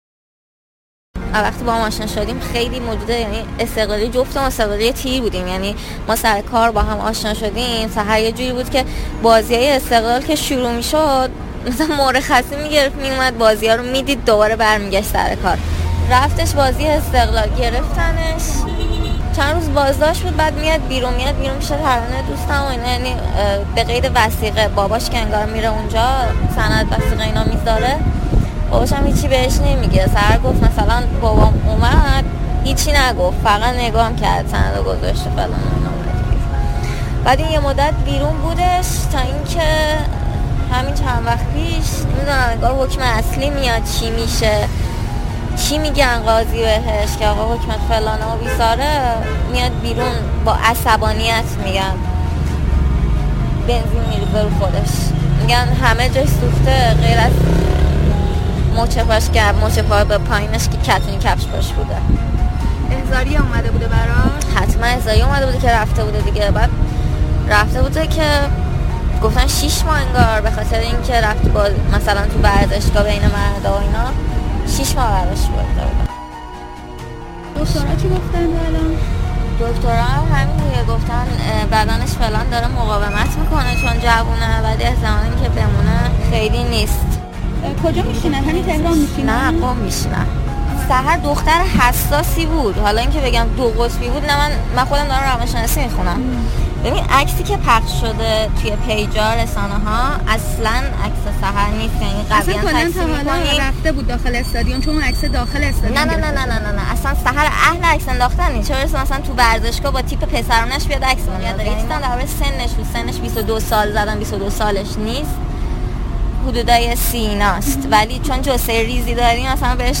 برخلاف روایت اعلام شده از سوی ج.ا. در دادسرا به ” سحر ” گفته شده بود باید خود را به زندان معرفی کند و وی از ترس زندان خود را آتش زد. صدای مصاحبه با دوست نزدیک سحر خدایاری